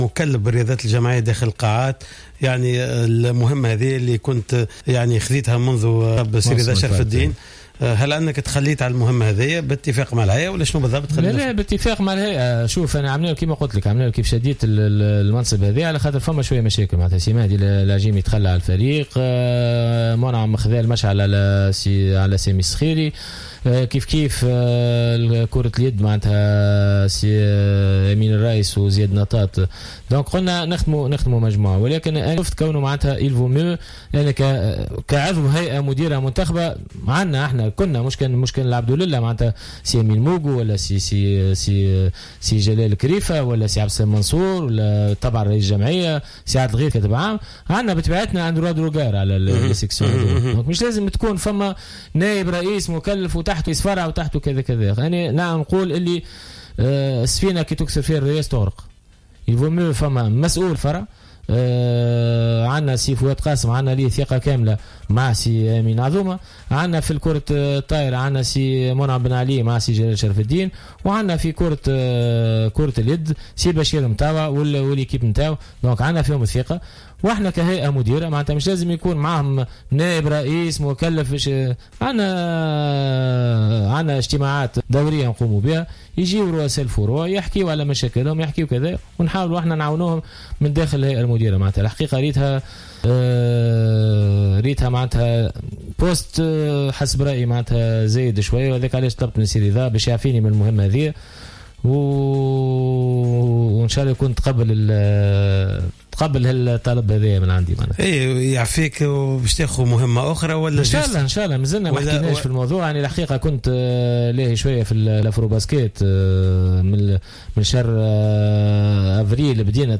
خلال إستضافته في برنامج راديو سبور